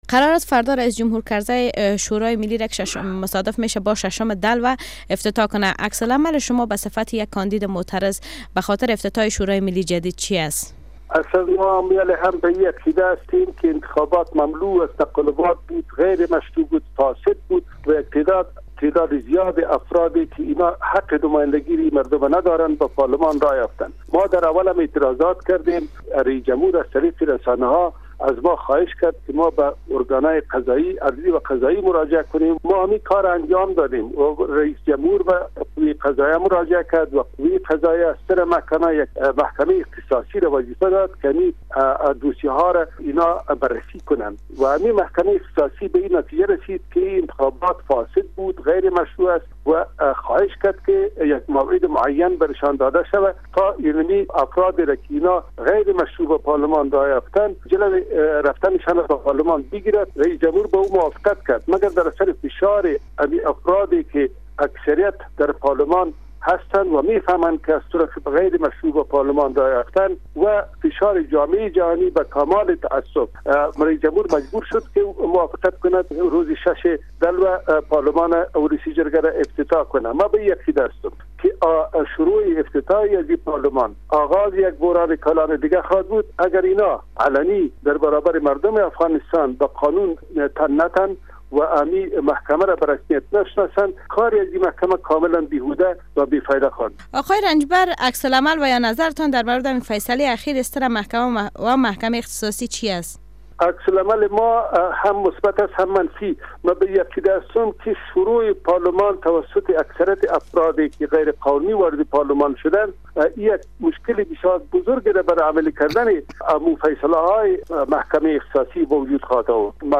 مصاحبه با عبدالکبیر رنجبر در مورد موافقت رییس جمهور در مورد افتتاح ولسی جرگه